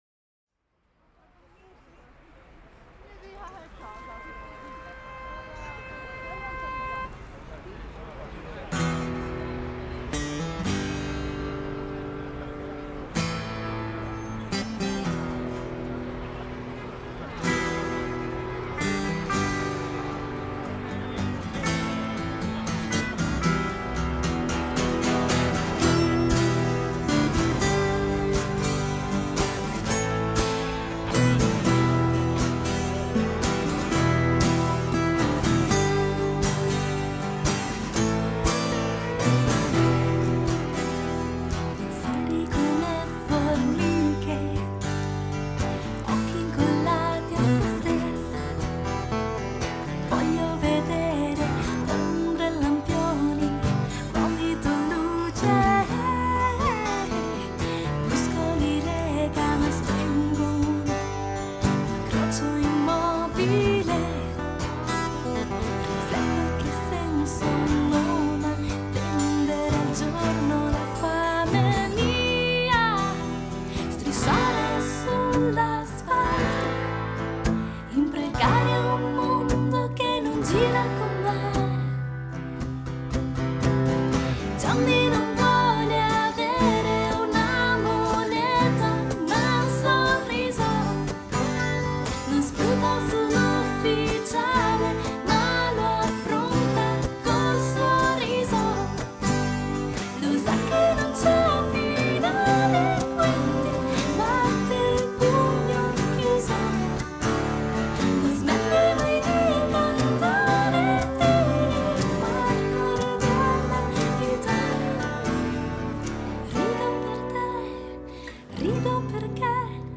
chitarre (solo)